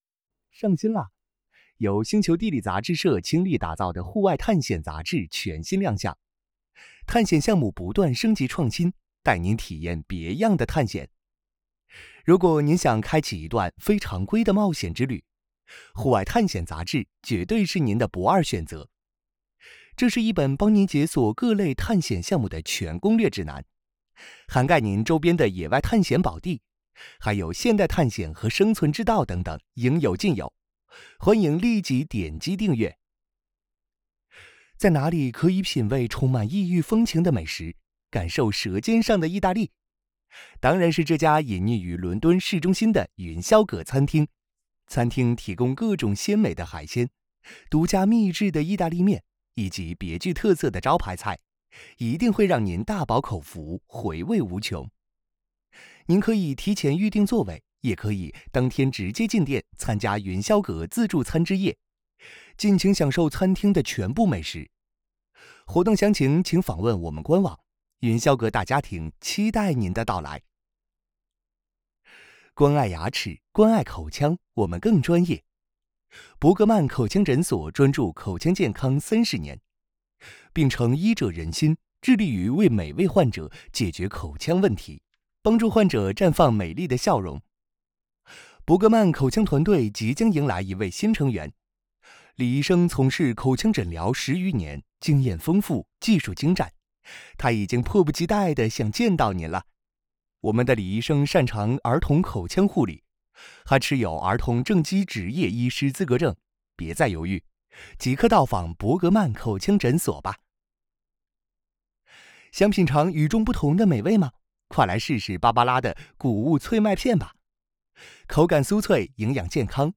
Chinese_Male_005VoiceArtist_20Hours_High_Quality_Voice_Dataset
Advertising Style Sample.wav